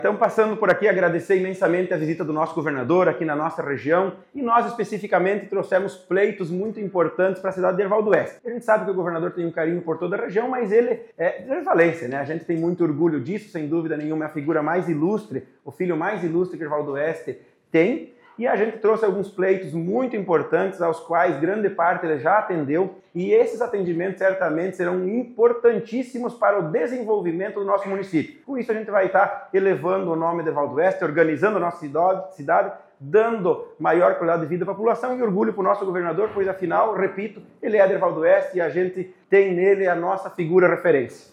O encontro foi em Joaçaba com os prefeitos e vice-prefeitos que compõem a Associação dos Municípios do Meio-Oeste Catarinense (Ammoc).
Após a conversa individual com o governador Jorginho Mello, o prefeito de Herval d’Oeste, Ronaldo Lourenço da Rosa, comentou sobre os recursos garantidos pelo Estado ao município: